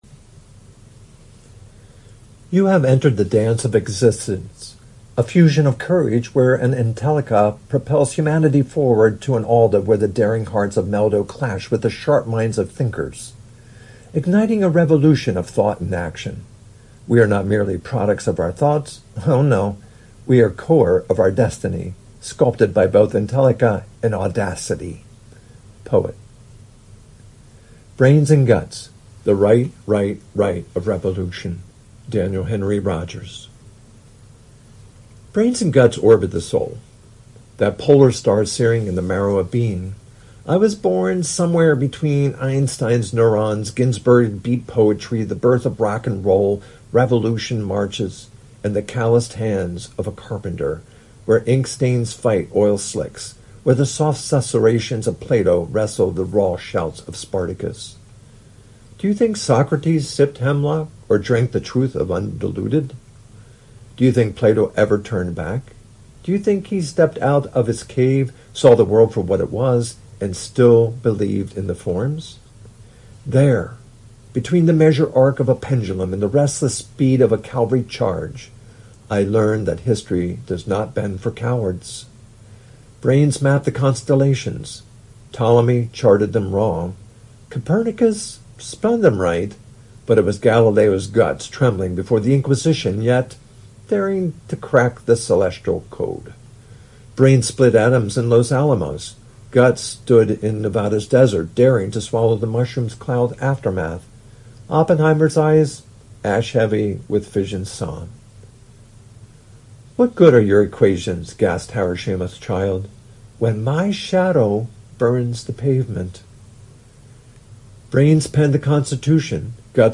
(I made an audio version since it is to be spoken)